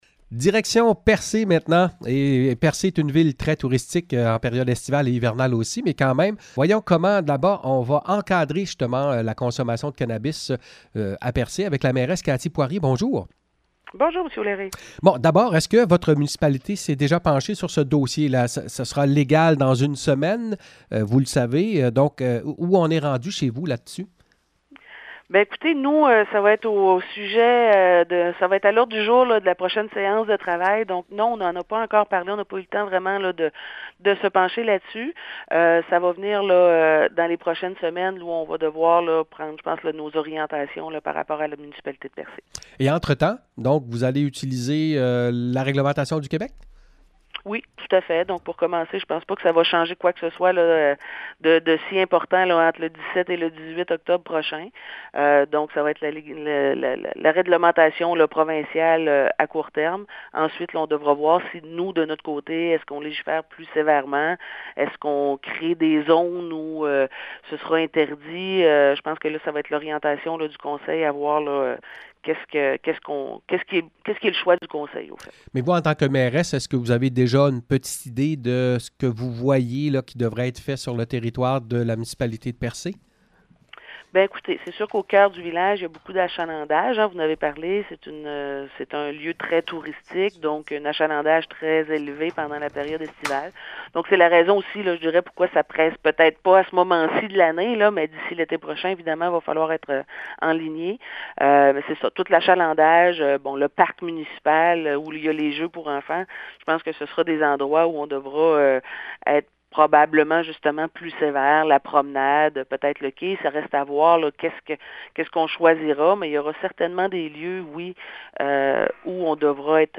Entrevue avec la mairesse de Percé, Cathy poirier: